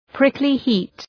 Προφορά
prickly-heat.mp3